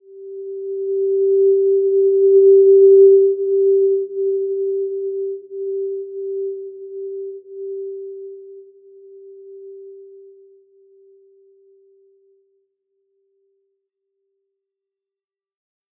Simple-Glow-G4-mf.wav